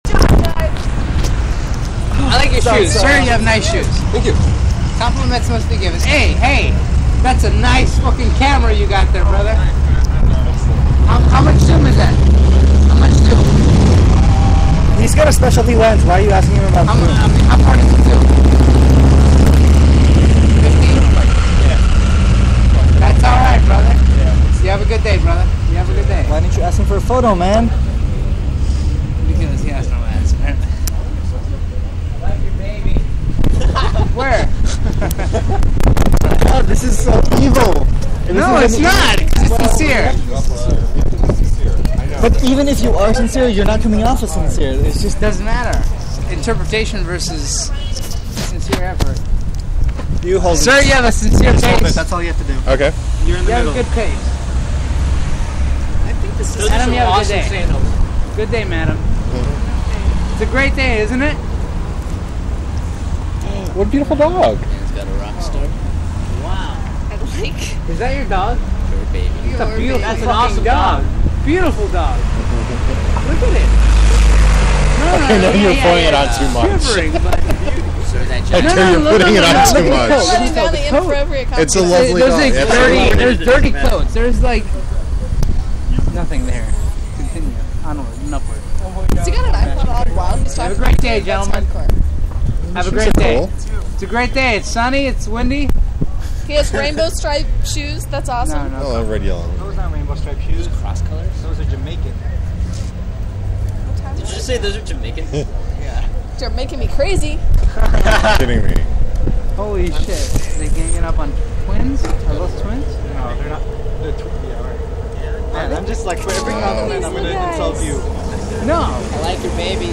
It was kind of windy, so what's posted is what was (semi-)audible.